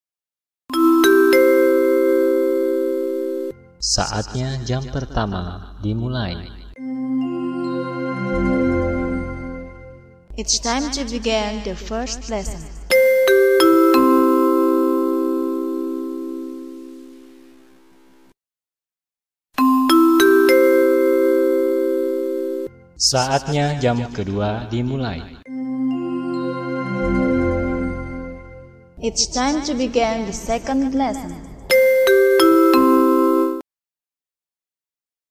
Nada Dering Suara Bel Sekolah Indonesia
Genre: Nada dering lucu
nada-dering-suara-bel-sekolah-indonesia.mp3